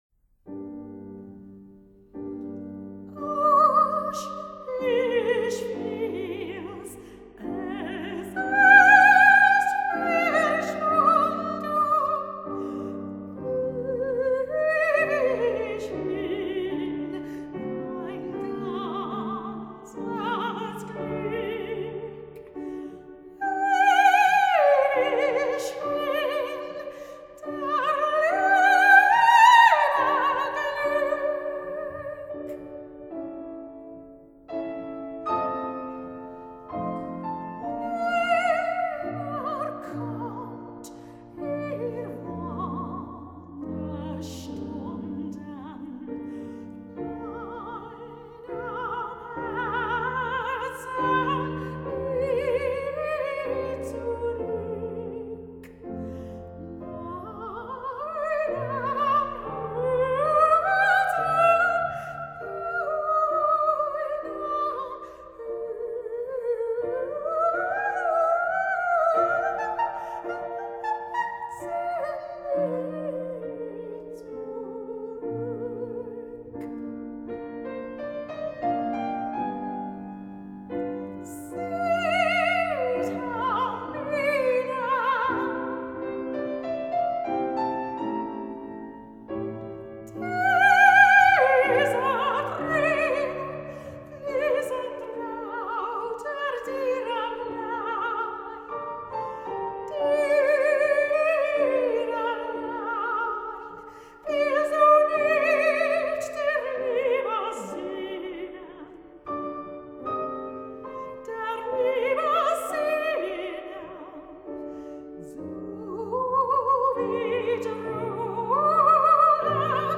Recorded in the Crypt, Canterbury Cathedral